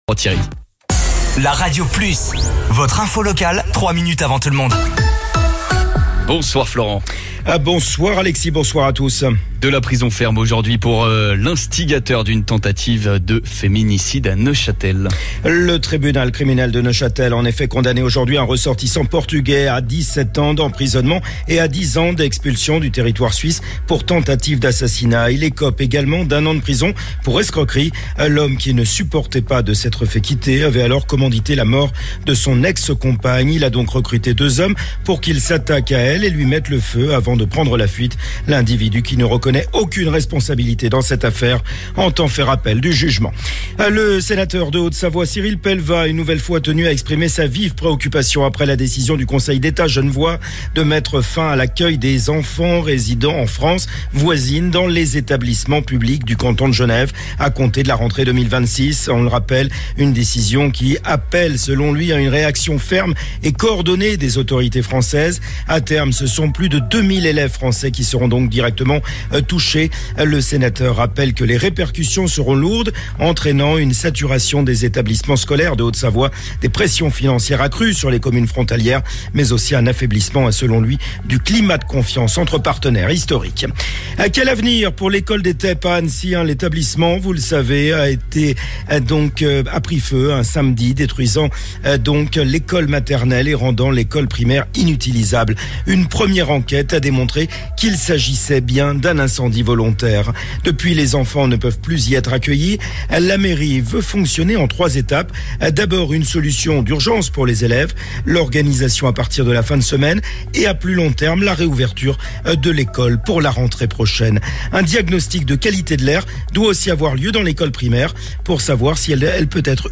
Votre flash info - votre journal d'information sur La Radio Plus